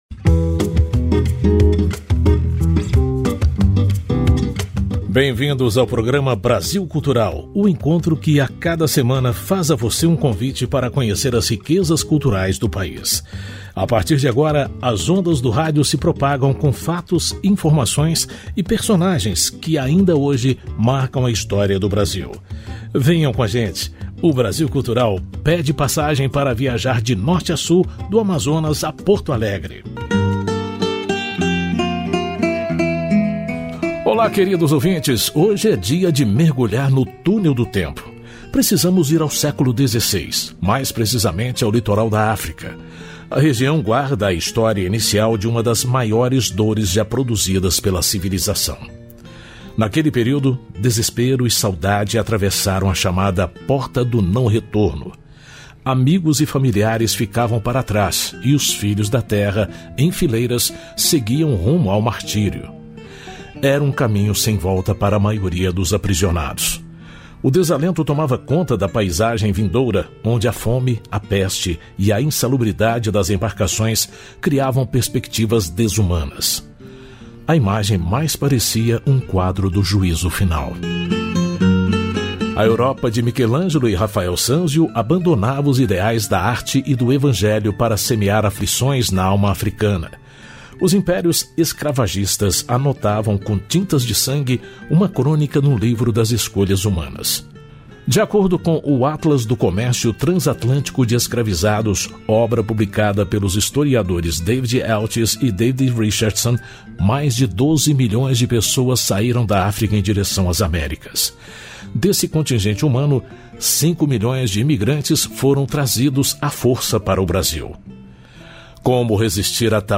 Neste episódio do Papo Saúde, nós vamos falar com o Secretário Adjunto de Vigilância em Saúde e Ambiente do Ministério da Saúde, Dr. Rivaldo Venâncio da Cunha, sobre como essa época do ano pode ser mais suscetível às chamadas arboviroses. 13/02/2025 | 06:03 compartilhar notícia Ouça na íntegra: Download Compartilhe essa notícia